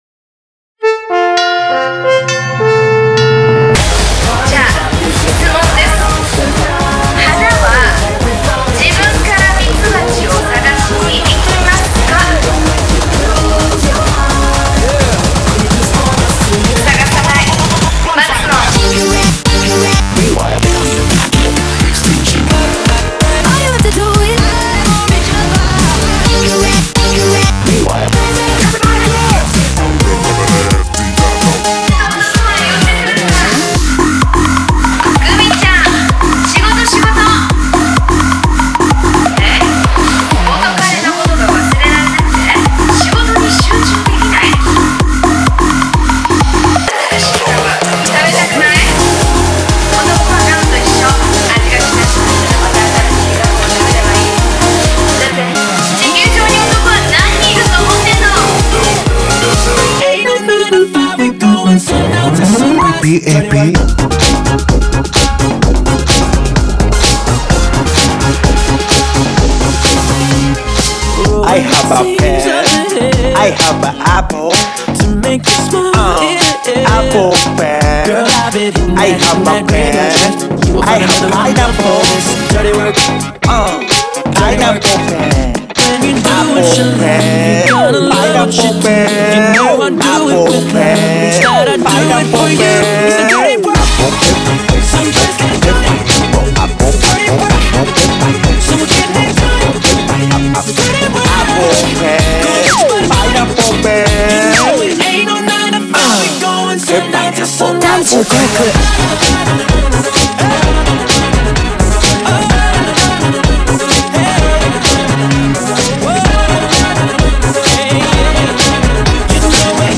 BPM128
Audio QualityPerfect (High Quality)
This is definitely an EDM mix.